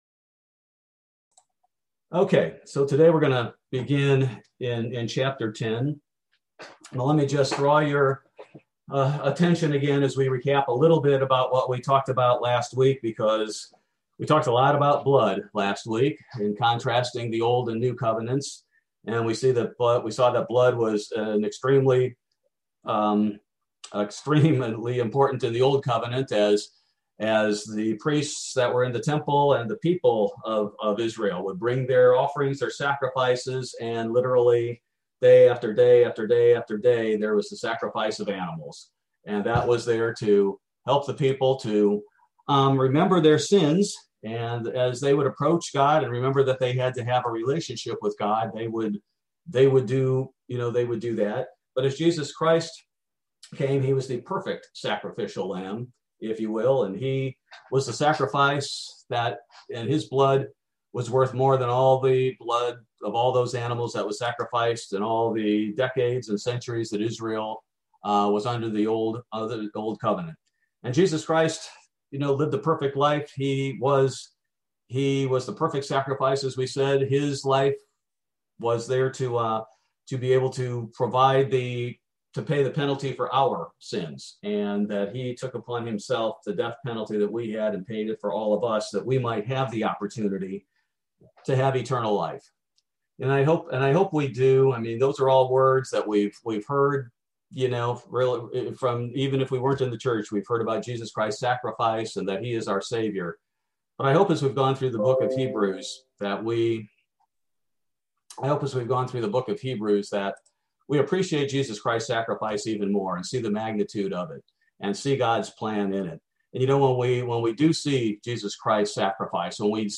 Bible Study - January 20, 2021